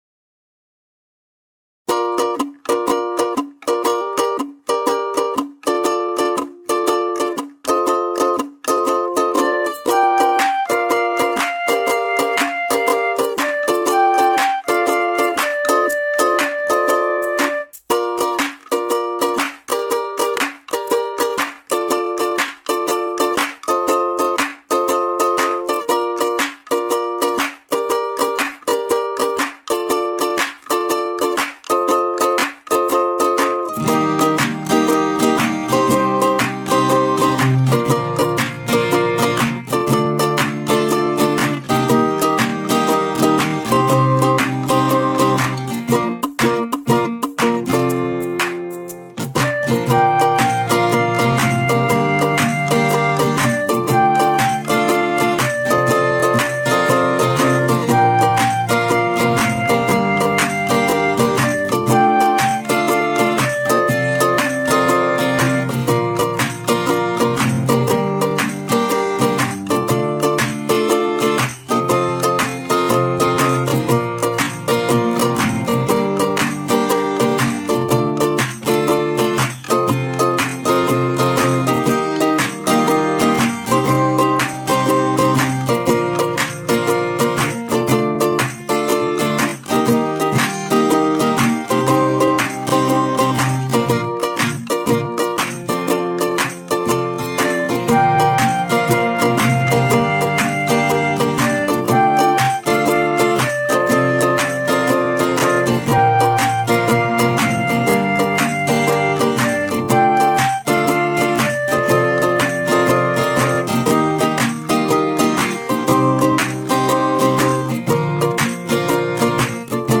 Une chanson pop